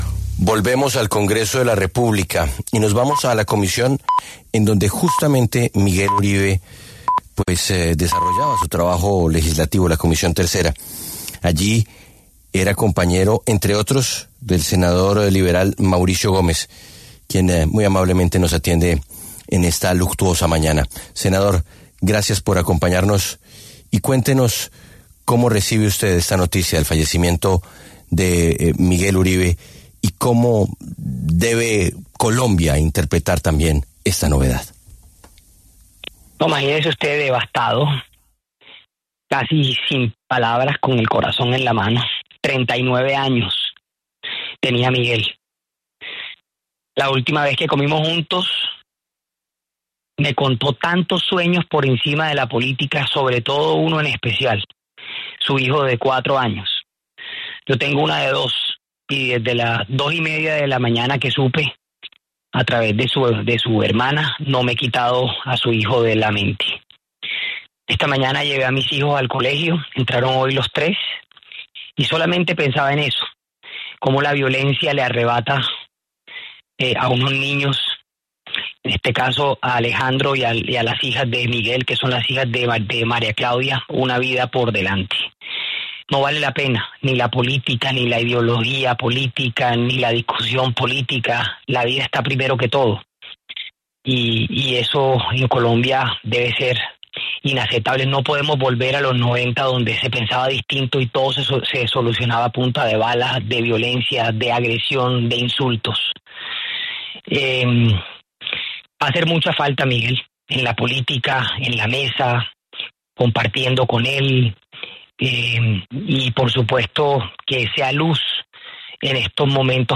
El senador Mauricio Gómez Amín se pronunció en La W a propósito de la muerte del senador Miguel Uribe Turbay.